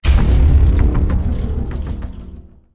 playerdies_7.ogg